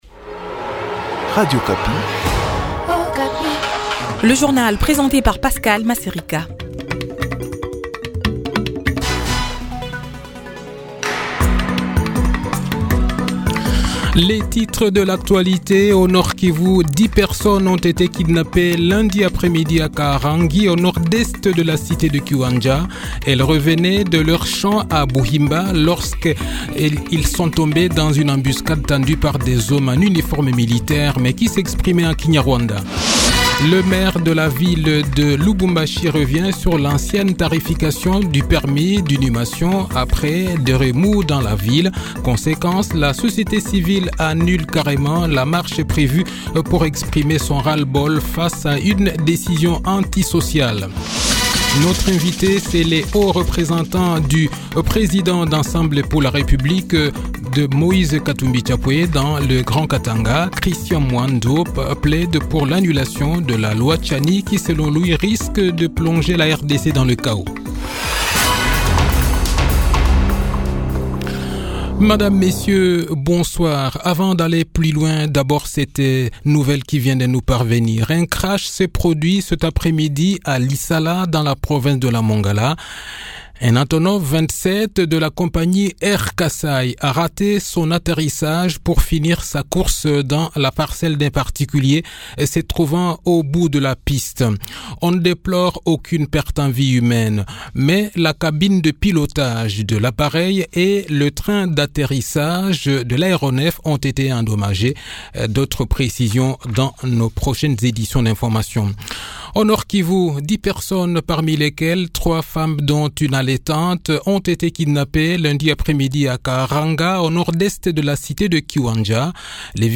Le journal de 18 h, 11 Avril 2023